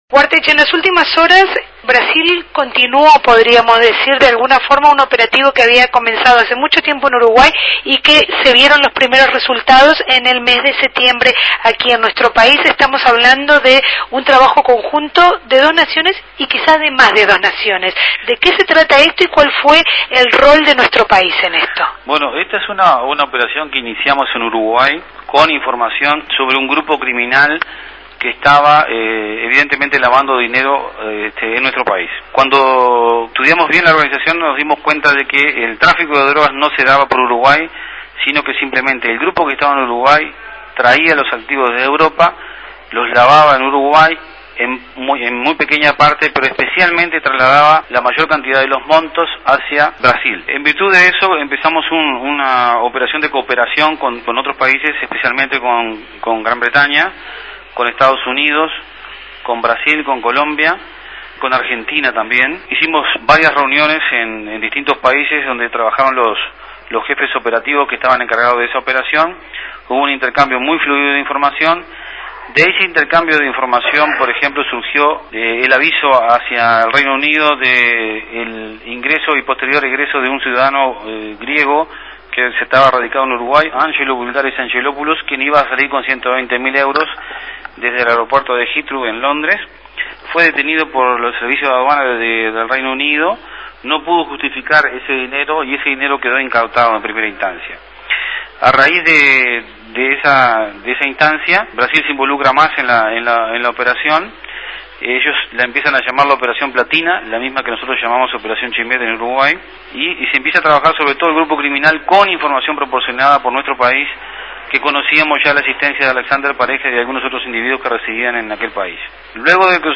Entrevista al Director General de Represión de Tráfico Ilícito de Drogas, Julio Guarteche.